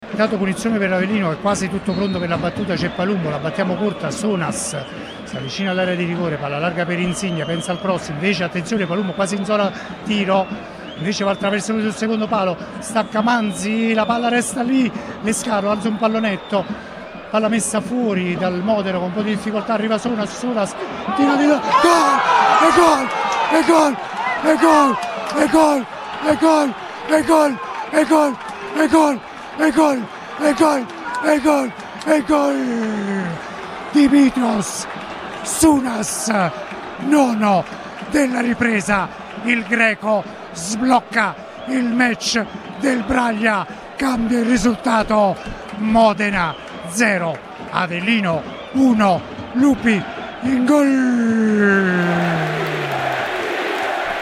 la radiocronaca e l’esultanza